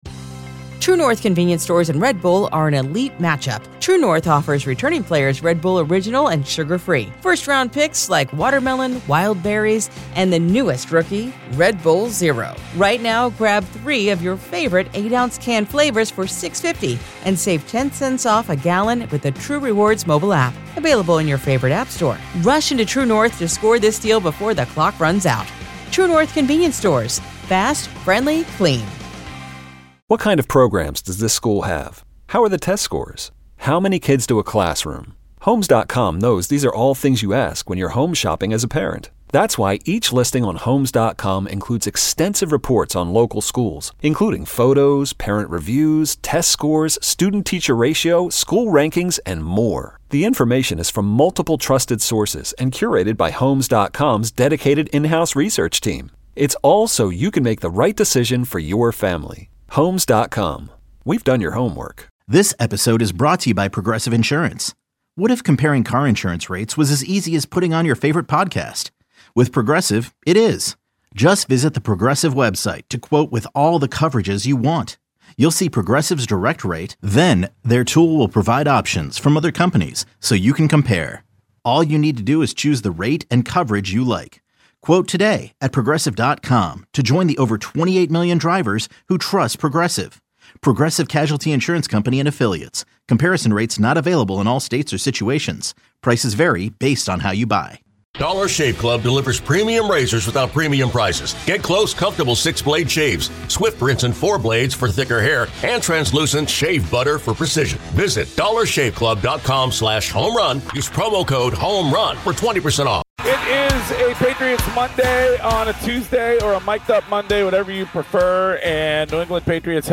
Best of WEEI Interviews: September 1 - September 5